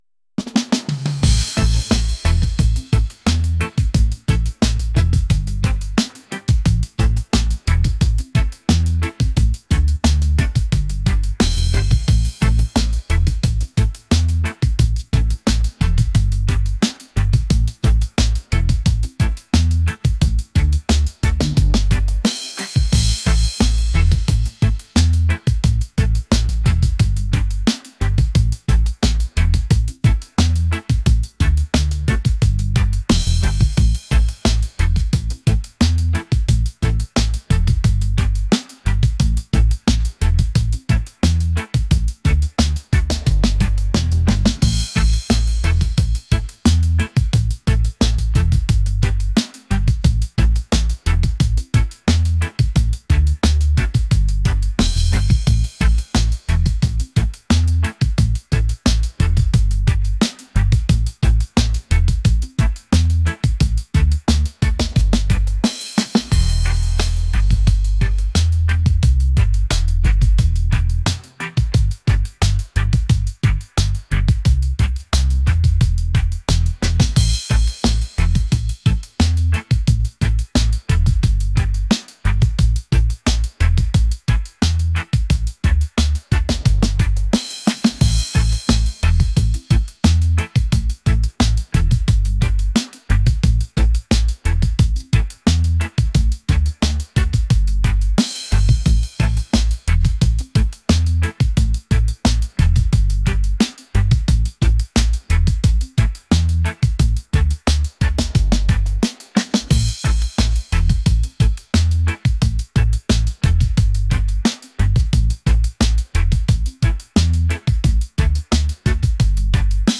groovy | reggae | laid-back